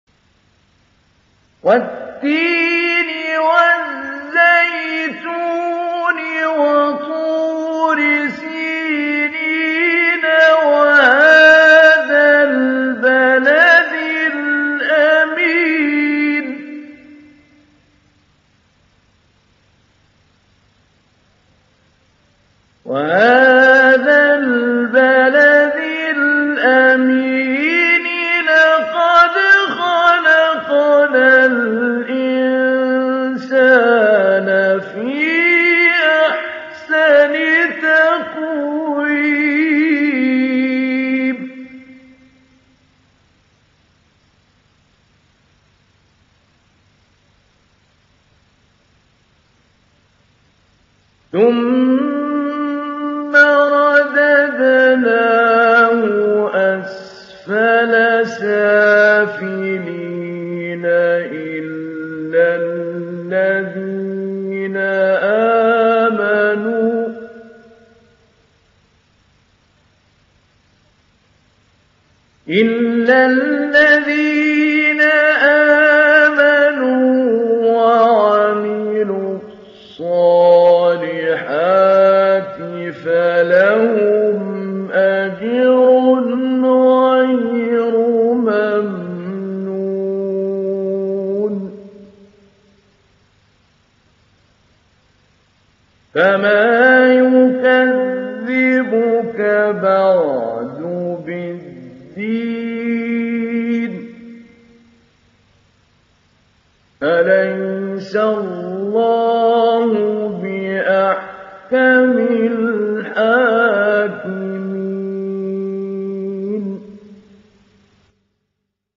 ডাউনলোড সূরা আত-তীন Mahmoud Ali Albanna Mujawwad